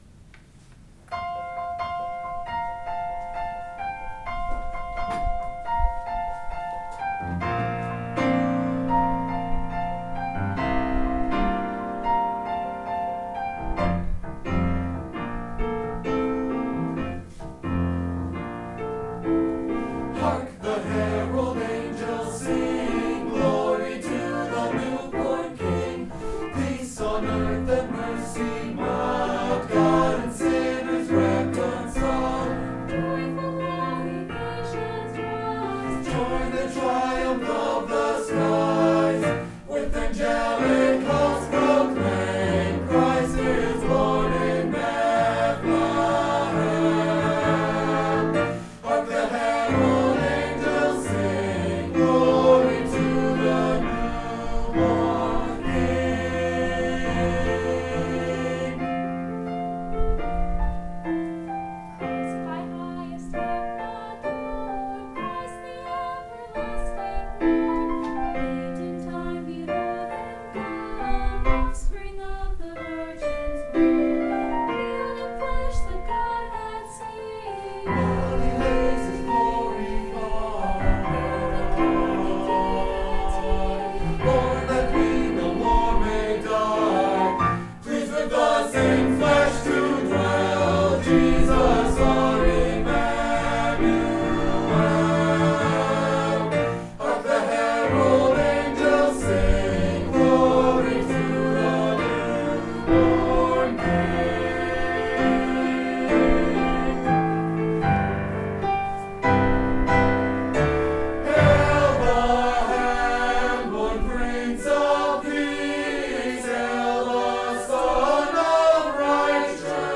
Choir
High School Choir Holiday Concert